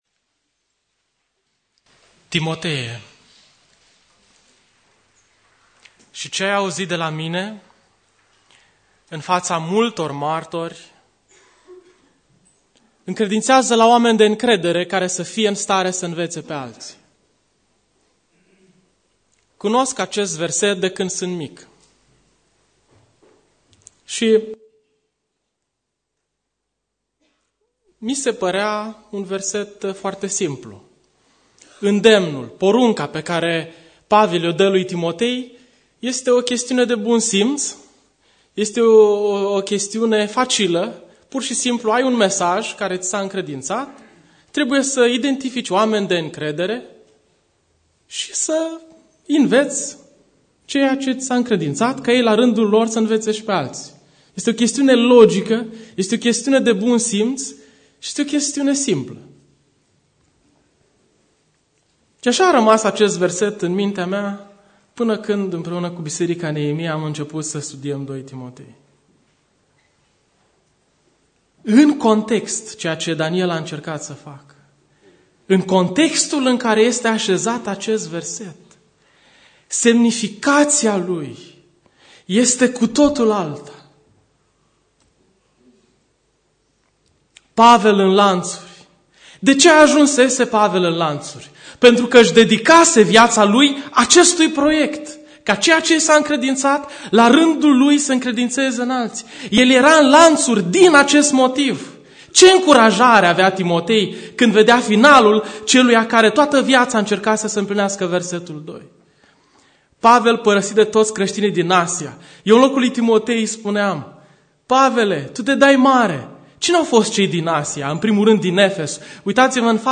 Predica Aplicatie 2 Timotei 2.1-2.13